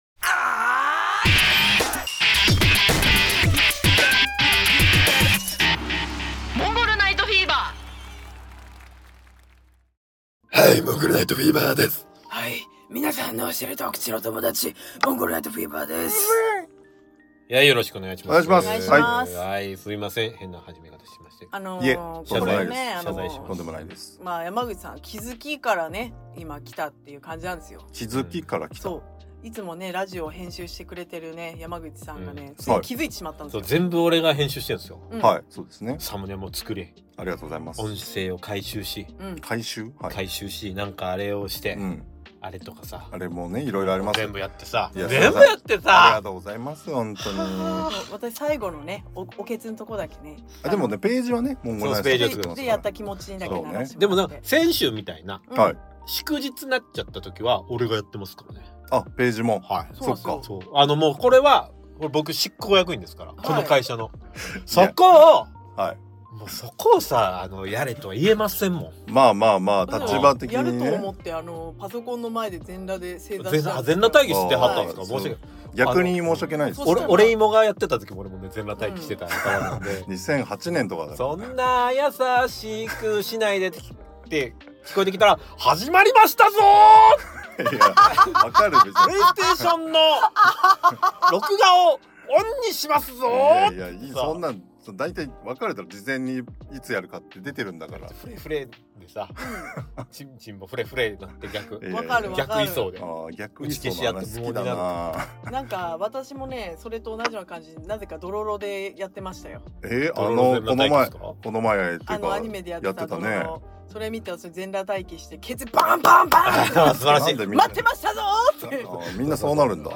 ・モンフィーはiPhoneで収録 ・音質が気になってゆく ・デートにあんらへんで ・プロジェクト・ヘイル・メアリーを語ろう！